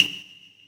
CASTANET.wav